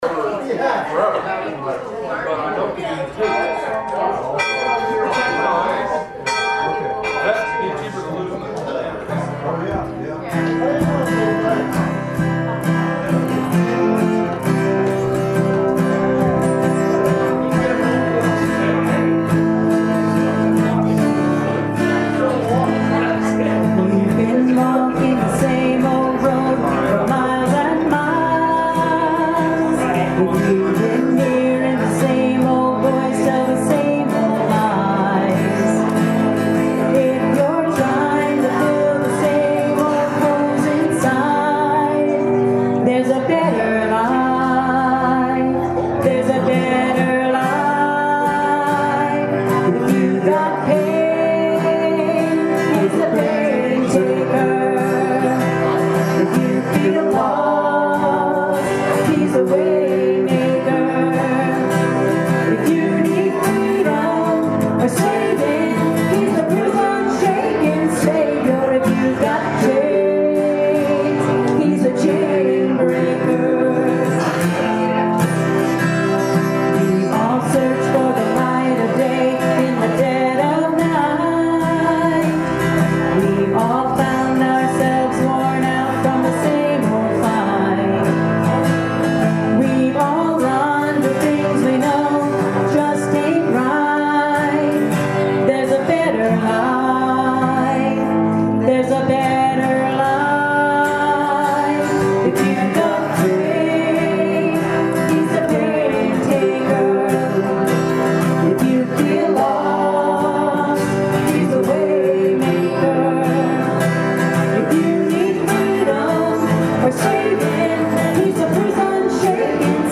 Benediction & Choral Amen